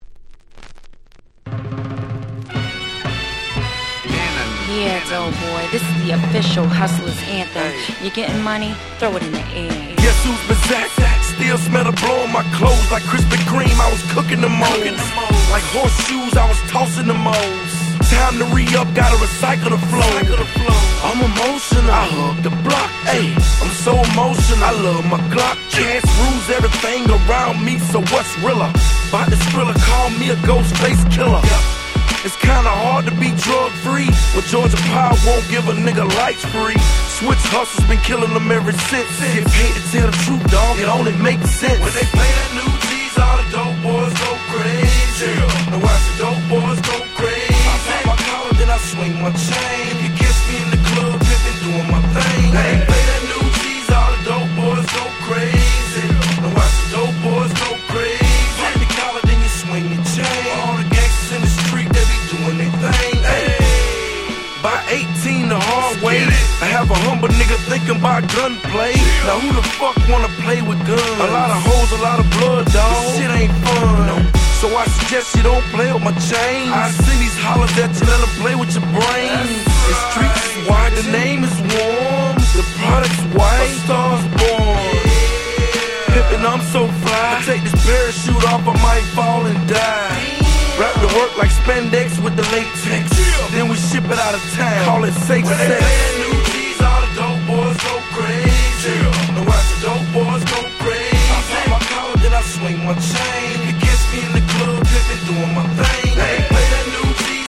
小傷によるプチノイズ箇所あり。)
05' Super Hit Hip Hop !!